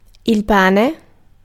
Ääntäminen
IPA: [pæ̃] France (Avignon)